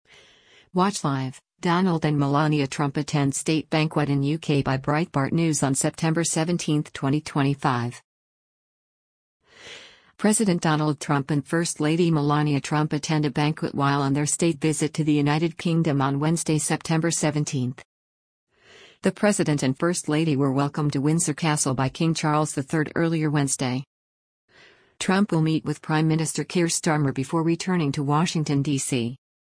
President Donald Trump and First Lady Melania Trump attend a banquet while on their state visit to the United Kingdom on Wednesday, September 17.